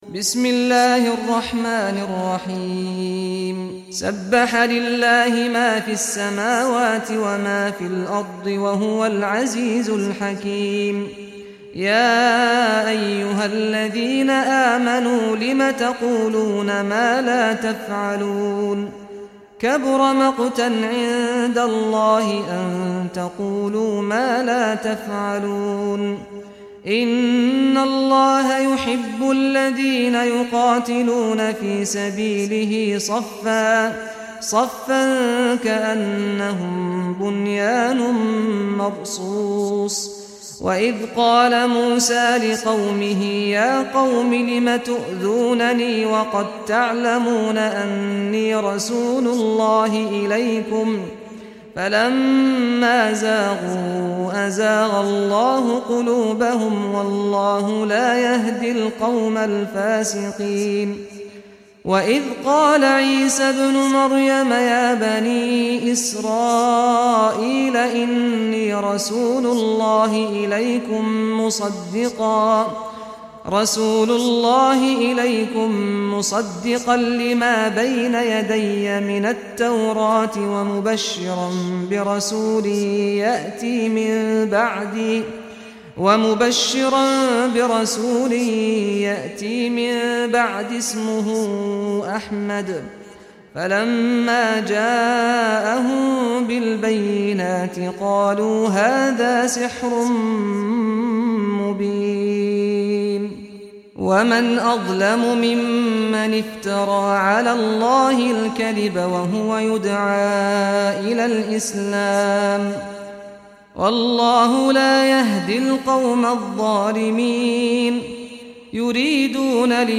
Surah As-Saff Recitation by Sheikh Saad al Ghamdi
Surah As-Saff, listen or play online mp3 tilawat / recitation in Arabic in the beautiful voice of Imam Sheikh Saad al Ghamdi.